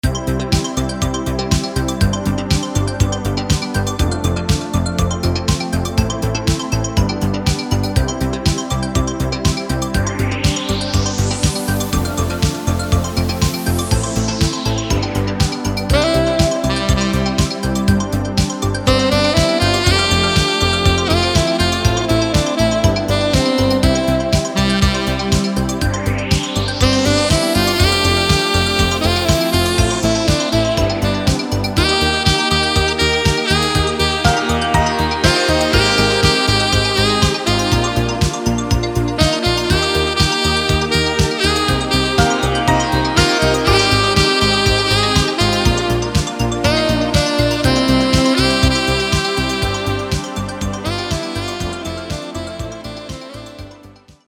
• Качество: 320, Stereo
громкие
мелодичные
Synth Pop
80-е
new wave